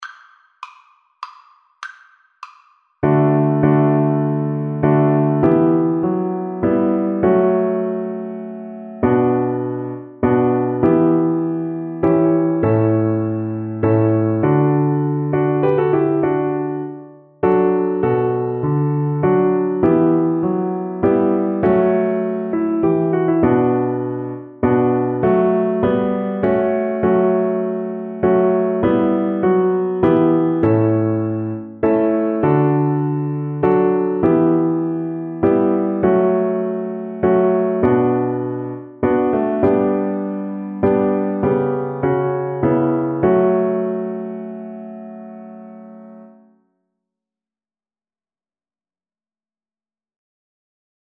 Violin
D major (Sounding Pitch) (View more D major Music for Violin )
Con moto
3/4 (View more 3/4 Music)
Traditional (View more Traditional Violin Music)
Israeli